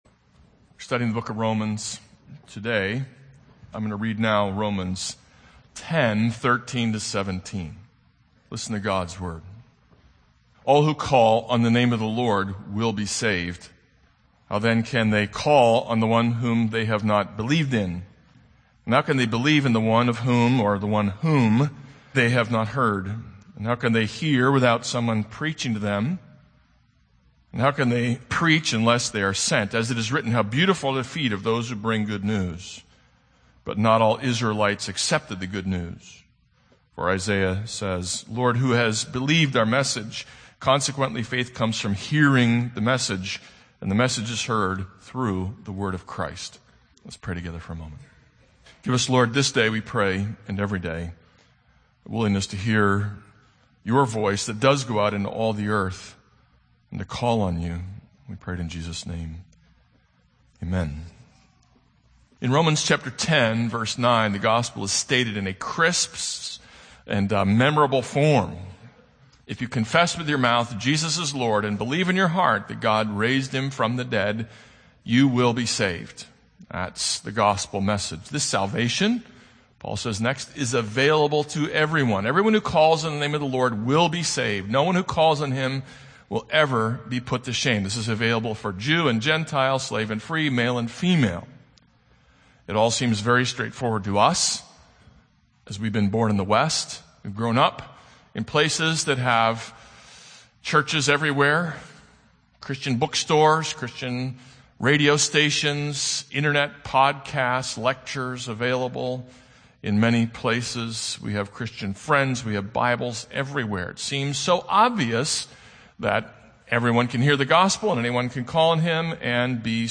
This is a sermon on Romans 10:14-21.